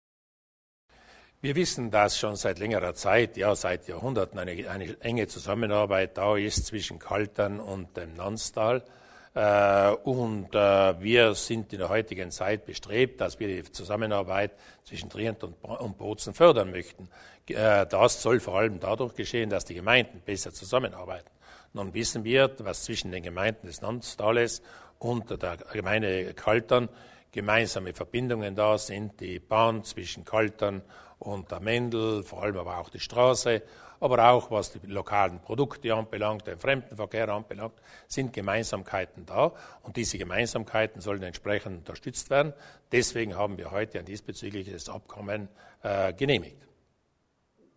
Landeshauptmann Durnwalder erläutert das Abkommen zwischen Kaltern und den Gemeinden am Nonsberg